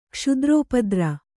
♪ kṣudrōpadra